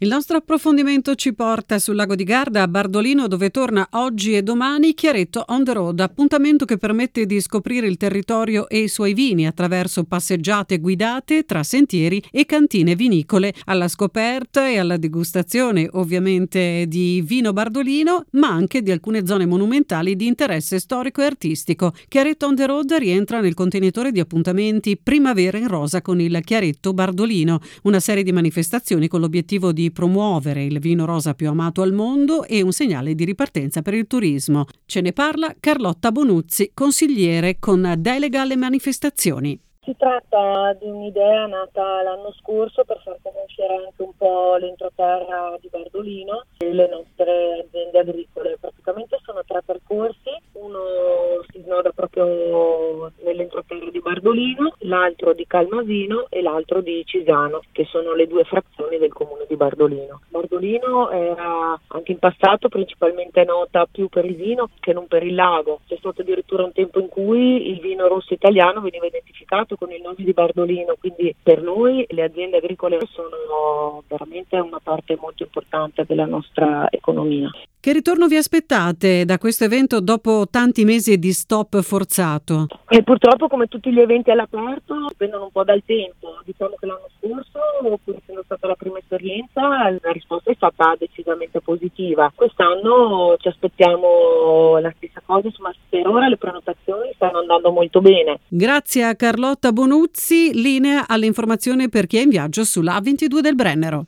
14 maggio: intervista a Carlotta Bonuzzi consigliere con delega alle manifestazioni del Comune di Bardolino, per Chiaretto on The Road, eventi per la promozione del vino e del territorio: